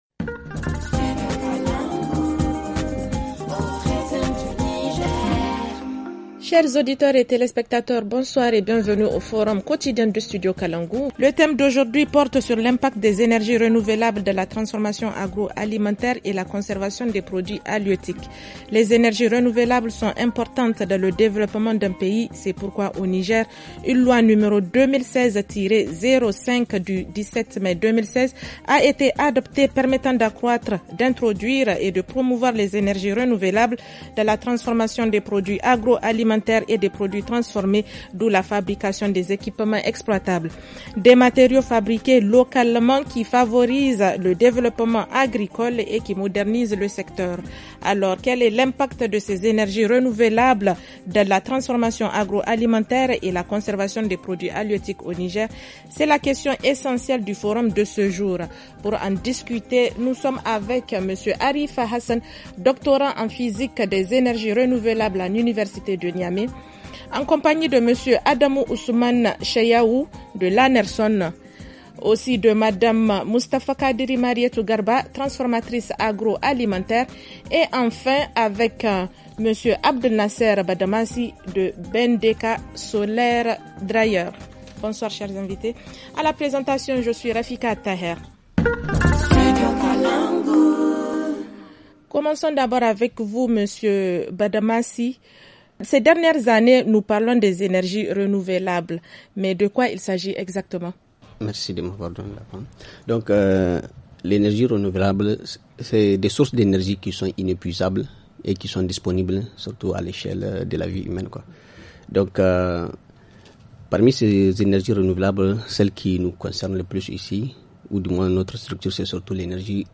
Le forum en français